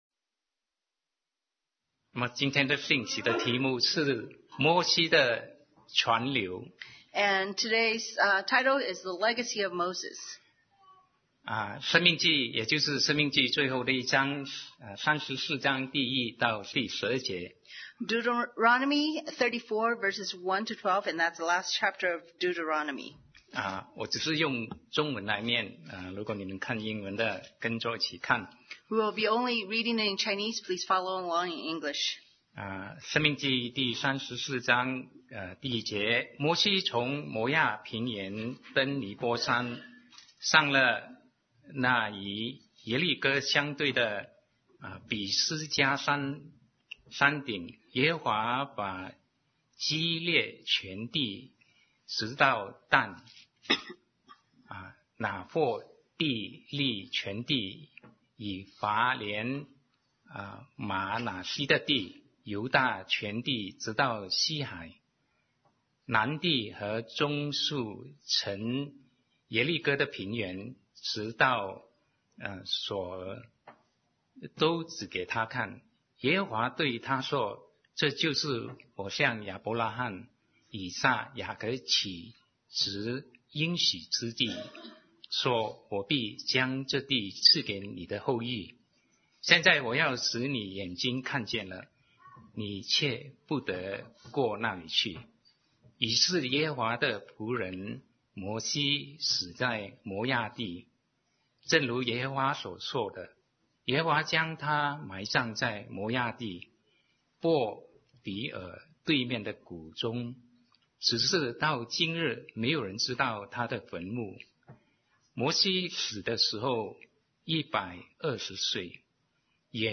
Sermon 2019-01-06 The Legacy of Moses